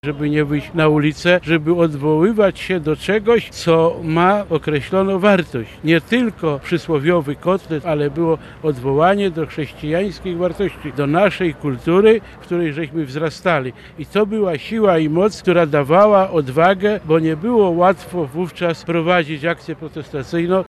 Związkowcy, kombatanci, przedstawiciele sejmu, władz rządowych i samorządowych uczcili Dzień Solidarności i Wolności. Uroczystości odbyły się pod Pomnikiem Wdzięczności w Lublinie.